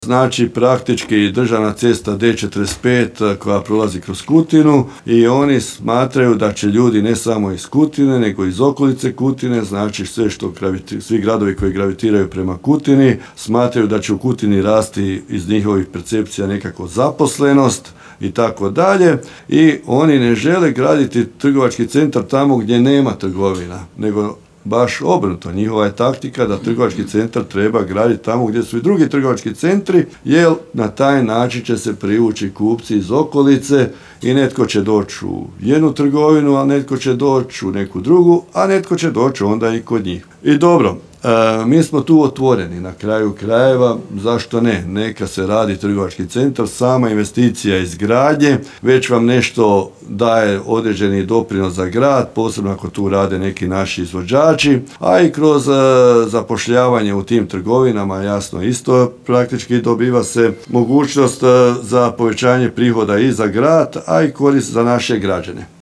Babić napominje kako pojedini investitori „gledaju Kutinu kao izlaz na autocestu”